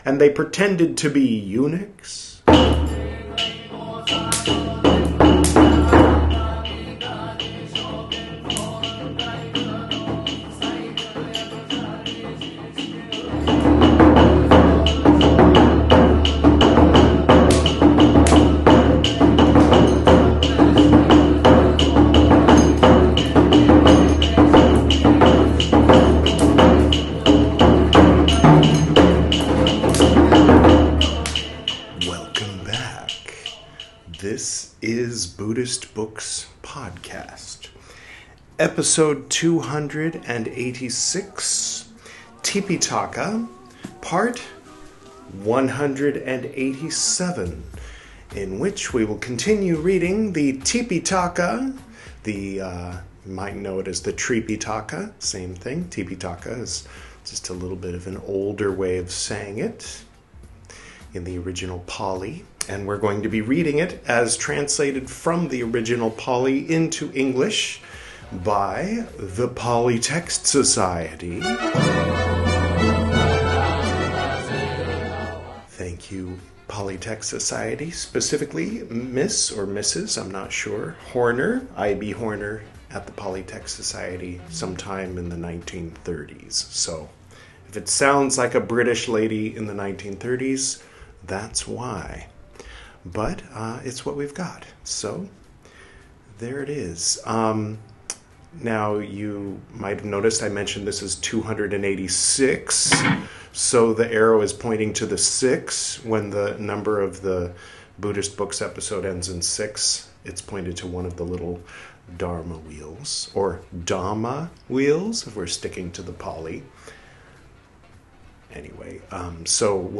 This is Part 187 of my recital of the 'Tipiṭaka,' the 'Three Baskets' of pre-sectarian Buddhism, as translated into English from the original Pali Language. In this episode, we'll finish reading 'Mahāvagga VIII,' from the 'Vinaya Piṭaka,' the first of the three 'Piṭaka,' or 'Baskets.'